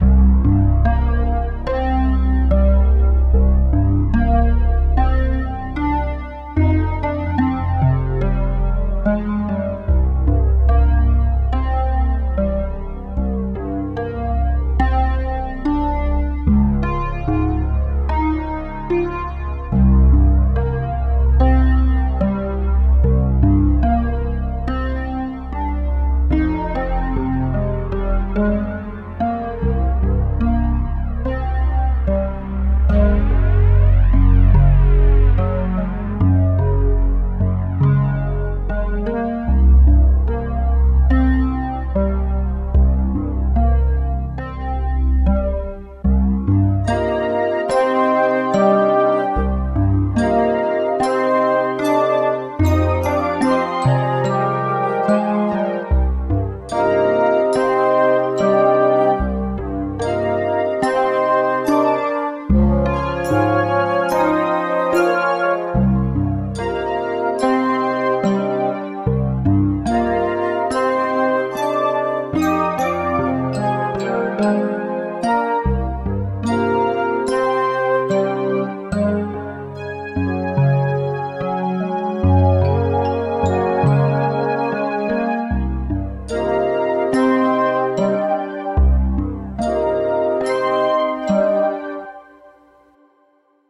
Village music